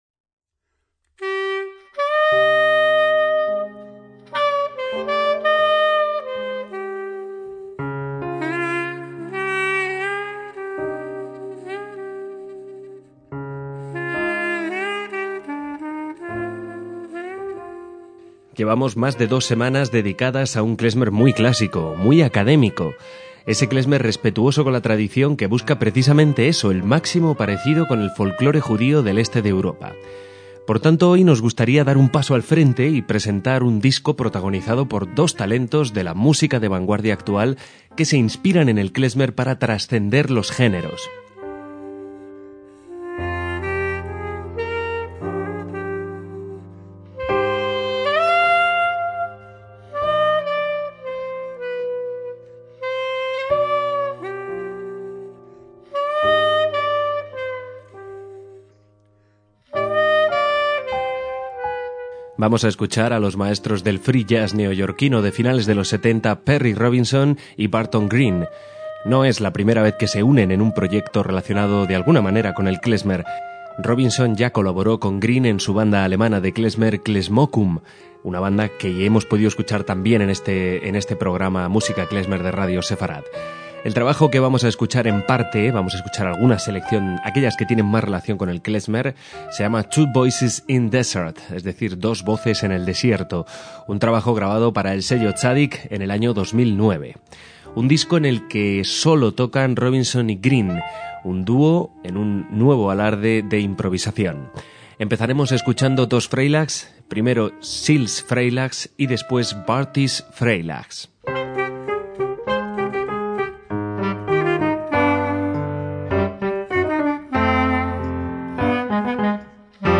MÚSICA KLEZMER
piano
clarinete, ocarina, flauta de madera, clarinete soprano
free jazz